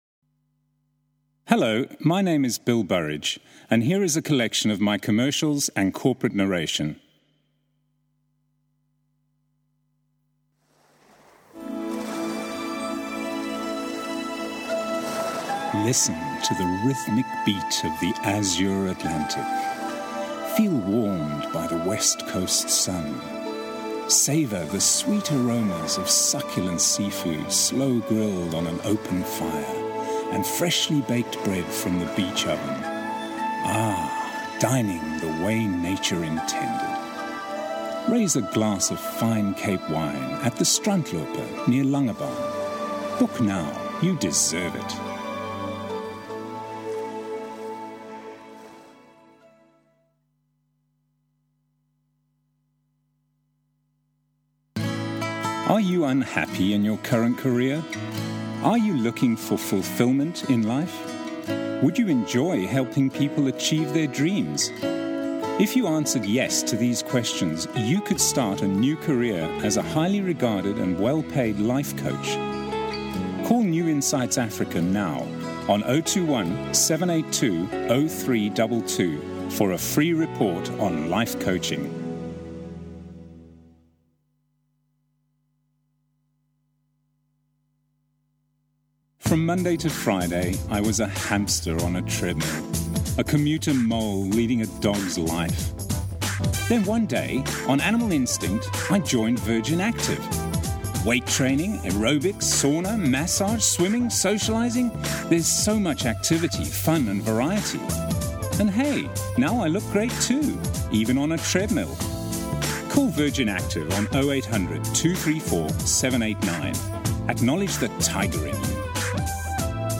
Voiceover*
Here is a collection of commercial and corporate narration demo voiceovers
Voiceover-demo.mp3